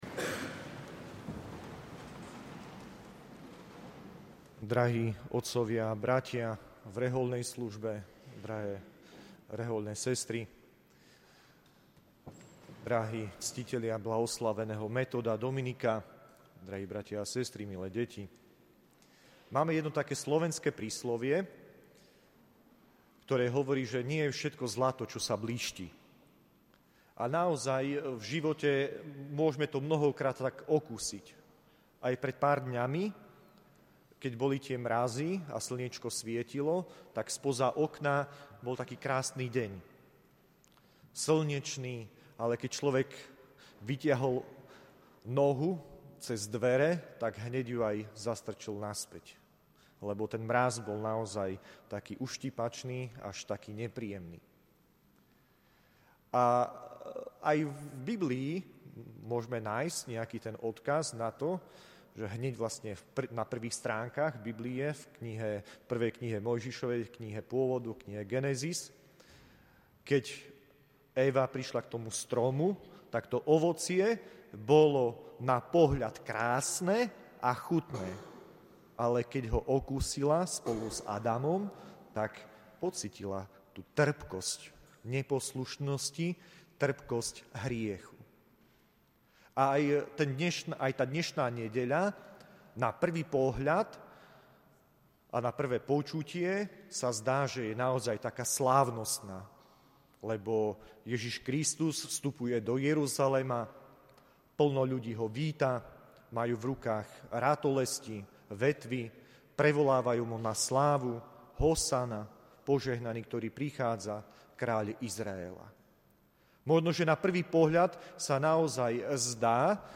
Príhovor